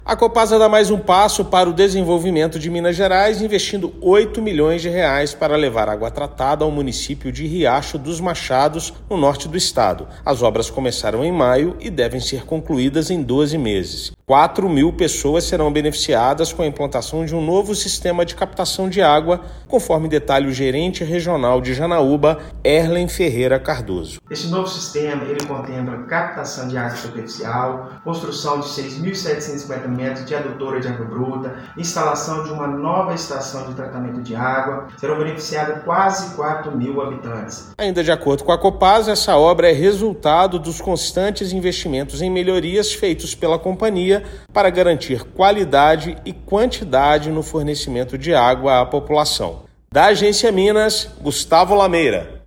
Obras já foram iniciadas e vão beneficiar 4 mil moradores do município no Norte de Minas. Ouça matéria de rádio.